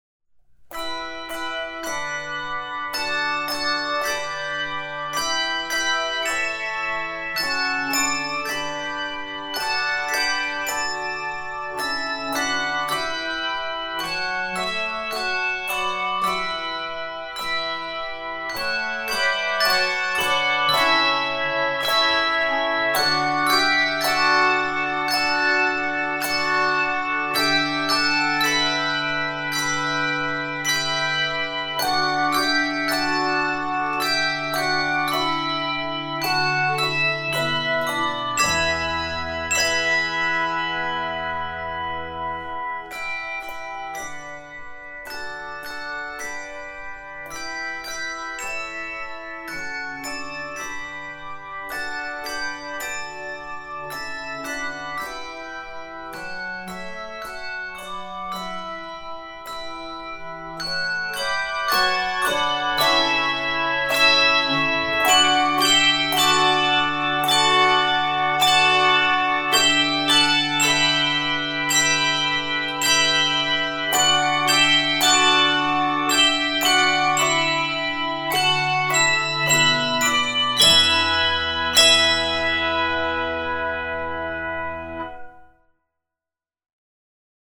Voicing: Handbells 3-7 Octave